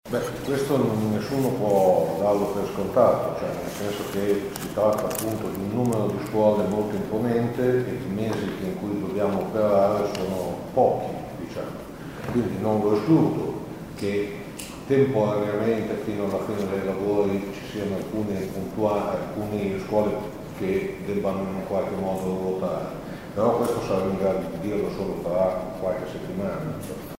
Rispondendo alla domanda di un cronista, l’assessore Malagoli ha spiegato che solo tra qualche settimana si potrà dire se tutti rientreranno nella propria scuola regolarmente: